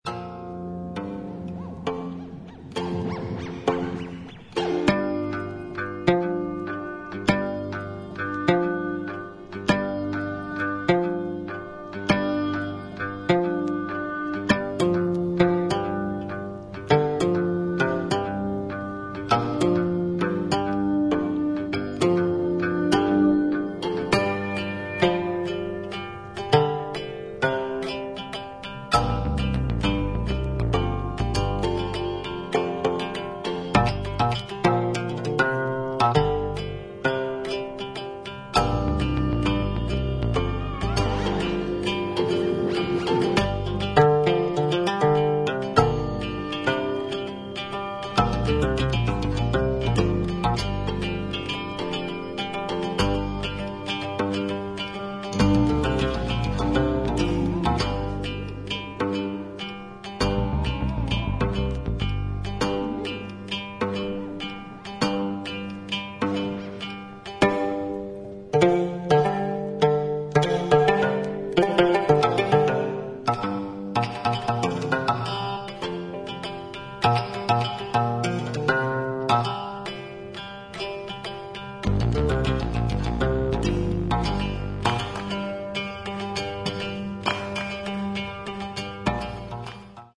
奥深く美しいイナンガの音色が軸となり、うっすらと入るエレクトロニック・サウンドやハンドクラップで展開する